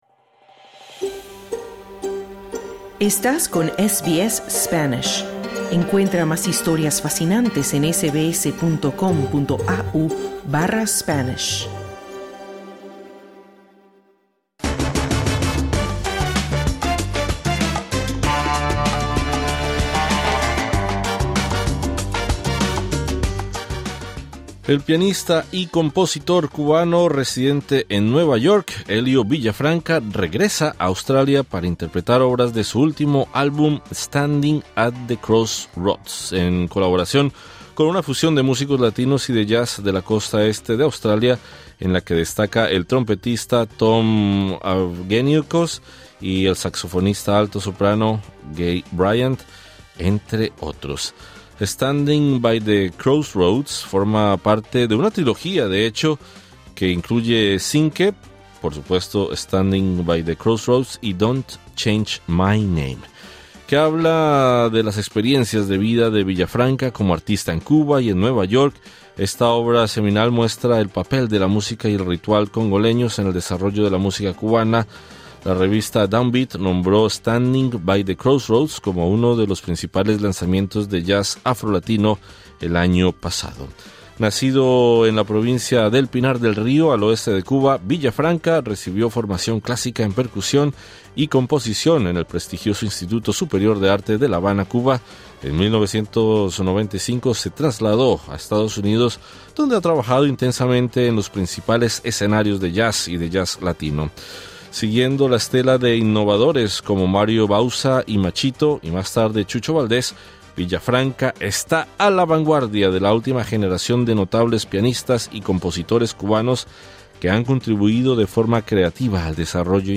Escucha la entrevista con el músico.